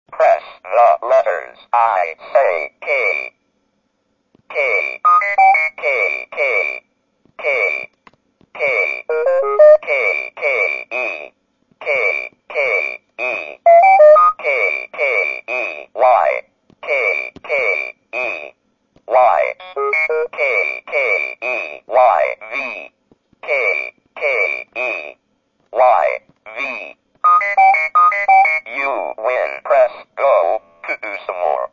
Texas Instruments invented around 1977 a novel approach to reproduce human speech with tuned voices stored in ROM’s (Read Only Memory). The speech synthesis circuit duplicated the human vocal tract on a single piece of silicon and with the Speak & Spell in 1978 a new talking learning aid for children was introduced.
Speech Phrases and Sound Samples of Unmodified Texas Instruments Products